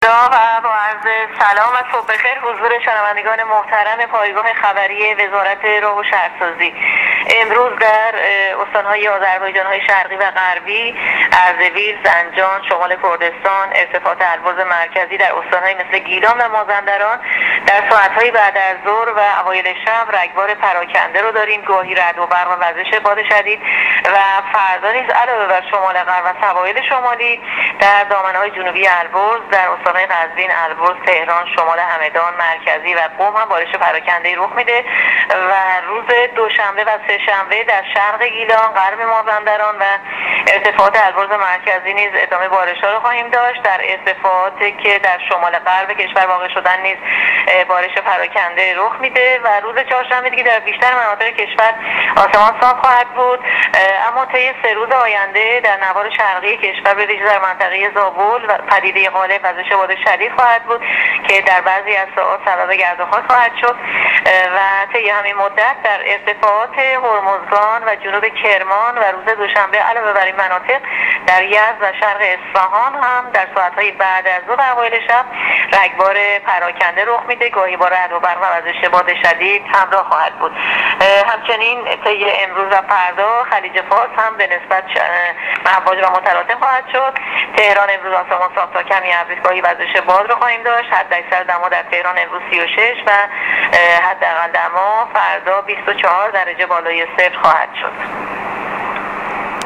گزارش رادیو اینترنتی از آخرین وضعیت آب و هوای ۱۴ تیر